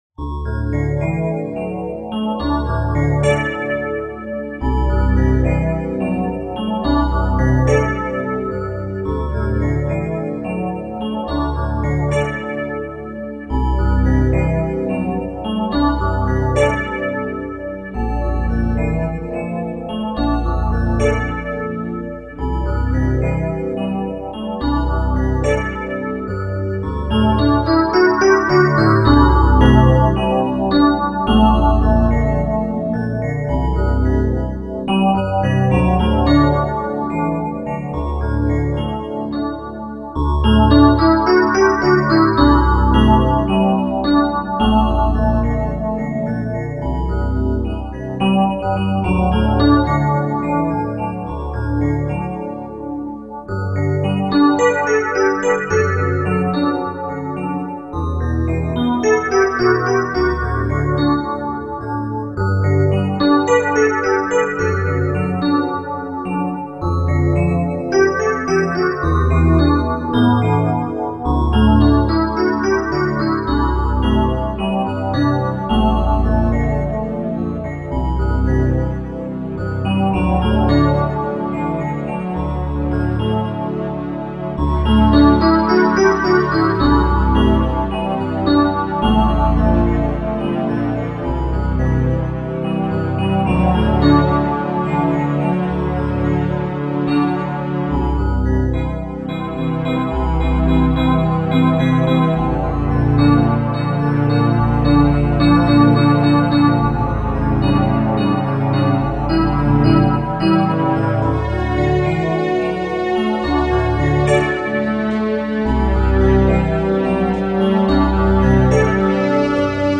Slow Mo